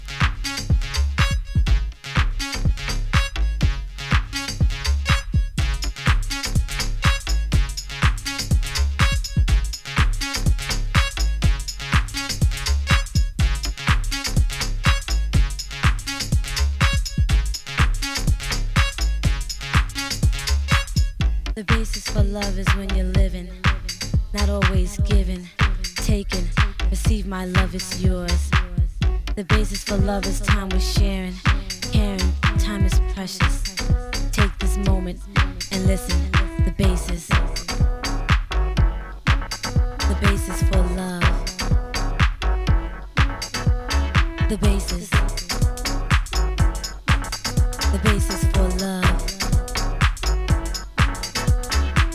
女性Voの妖しいDeep Houseトラック